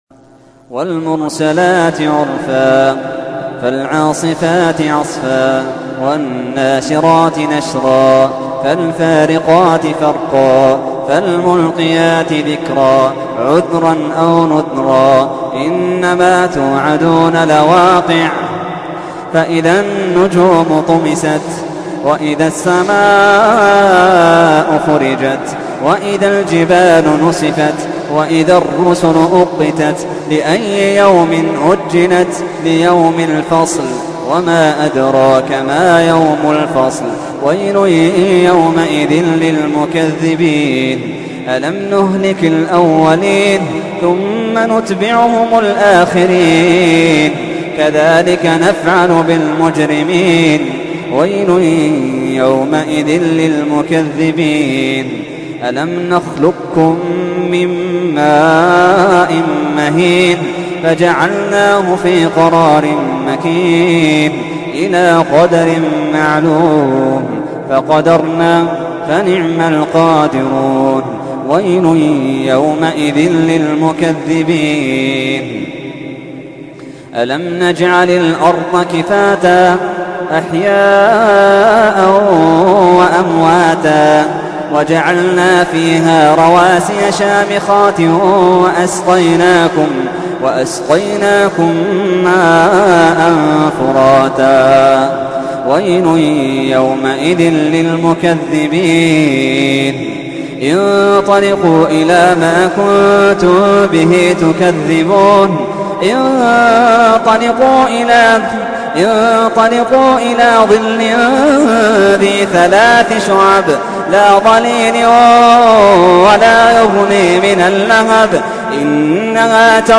تحميل : 77. سورة المرسلات / القارئ محمد اللحيدان / القرآن الكريم / موقع يا حسين